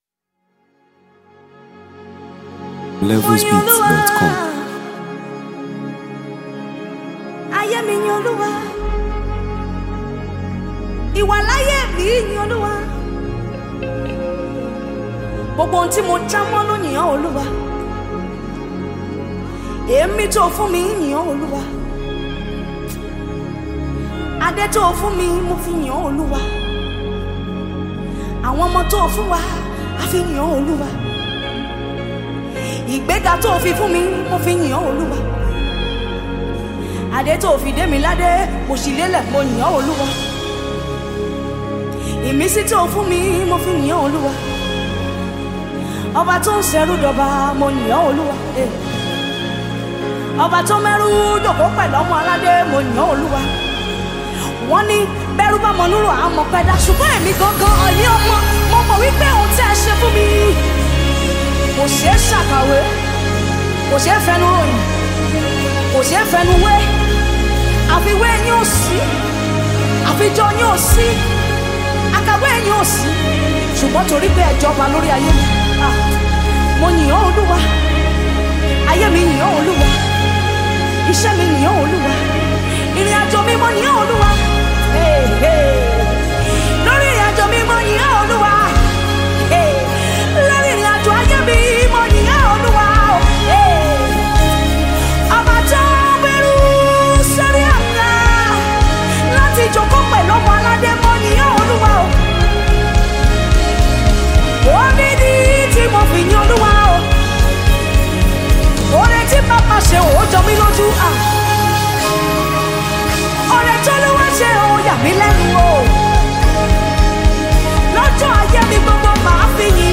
Deeply anointed Nigerian gospel singer and worship minister
Sung with deep spiritual intensity
creates an atmosphere of joyful worship and thanksgiving.